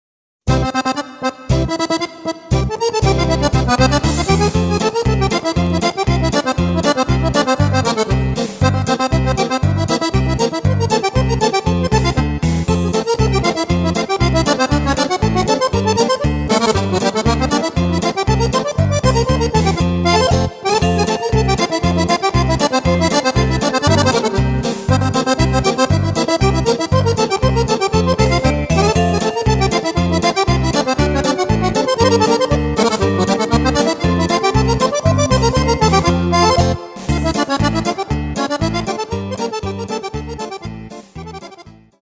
Elettrica D .mp3